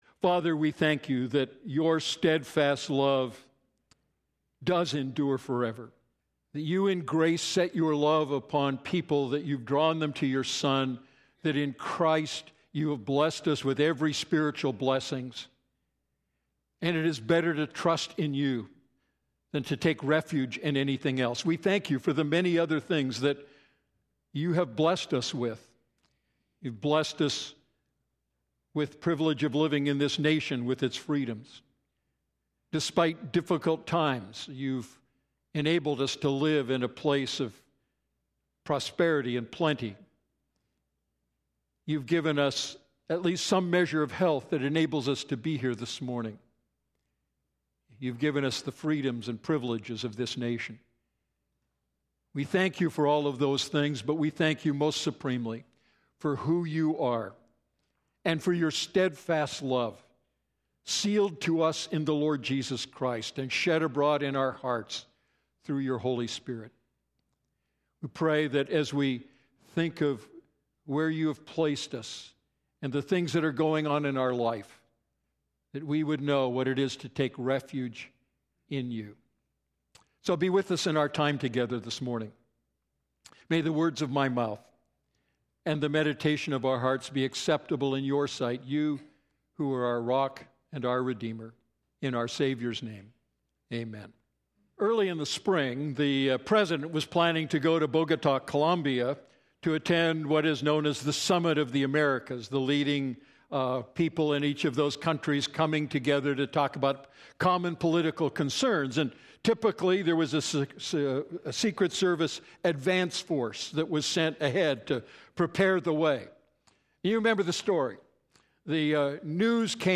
A message from the series "Going for the Gold."